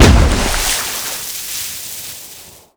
land_on_water_4.wav